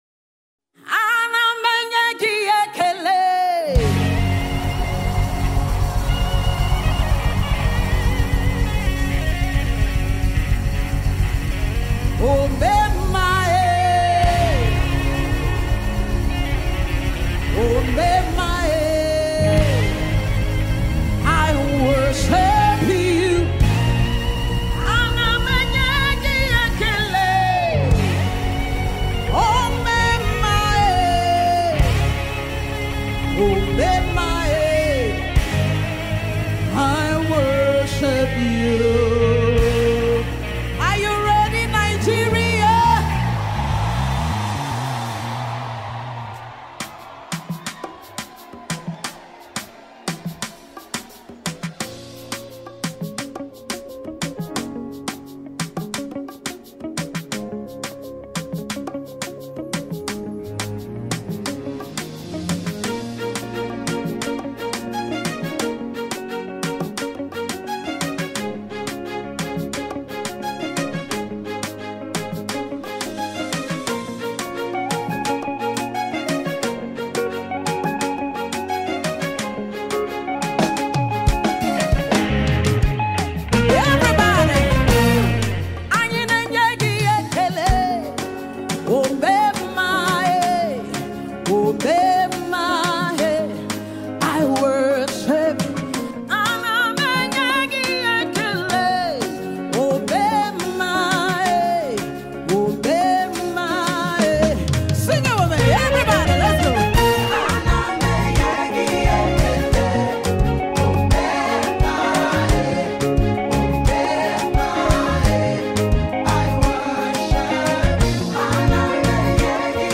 Talented Nigerian gospel singer